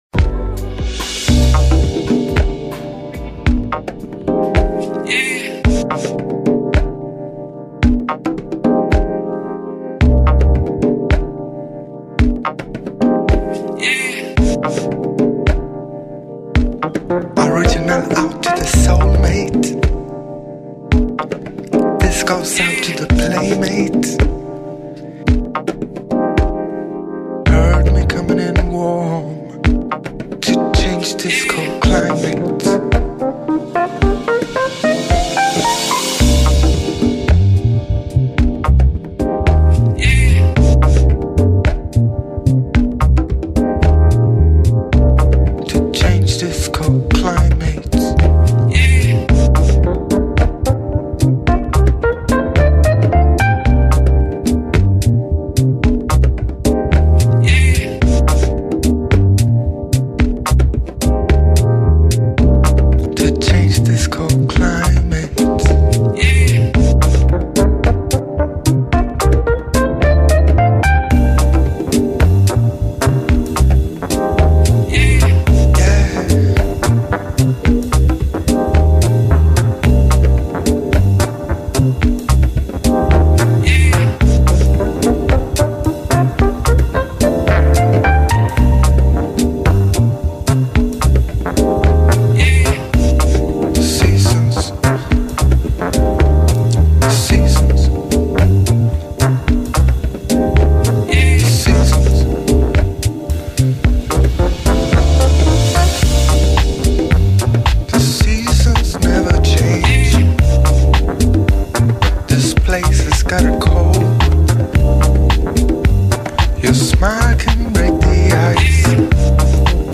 音乐类型：西洋音乐
甜美中氤蕴迷幻，舒适解压的聆听旅程。